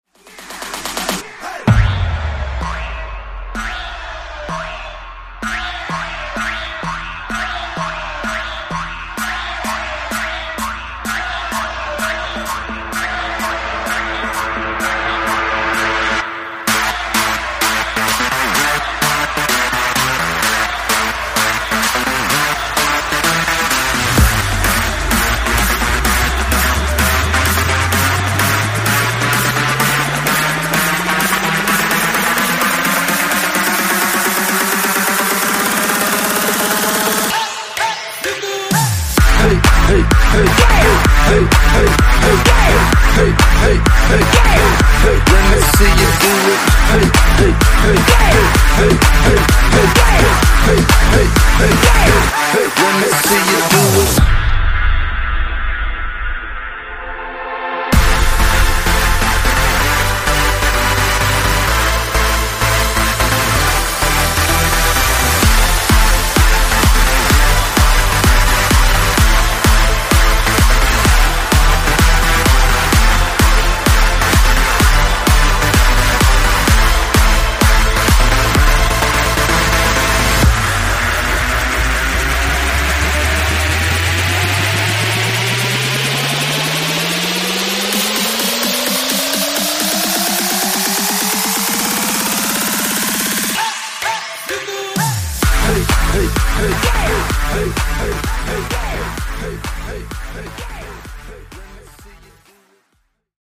Genre: RE-DRUM Version: Clean BPM: 71 Time